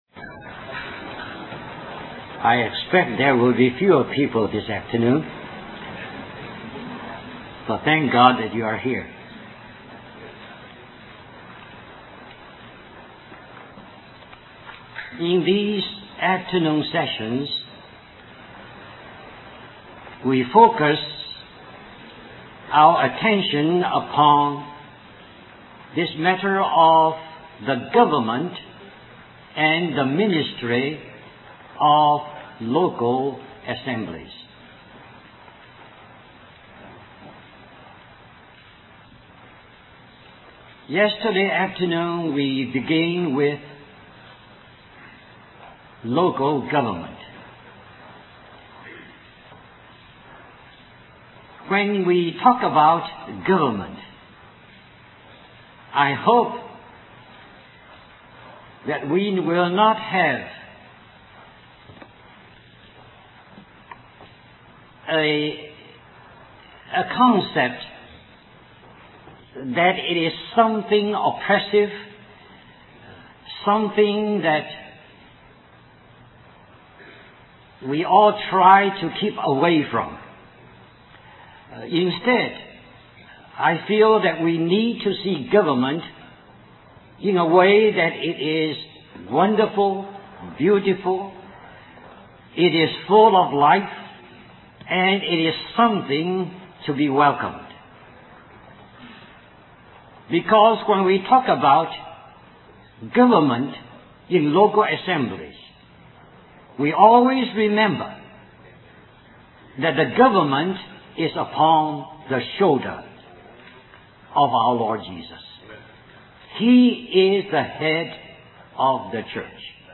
1991 Christian Family Conference Stream or download mp3 Summary The government of the church is on the shoulders of Jesus Christ.